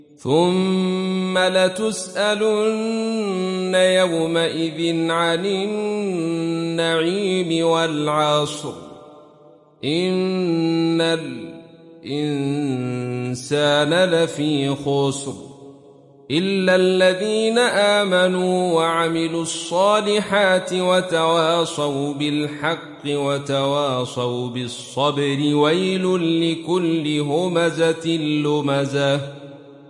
Khalaf থেকে Hamza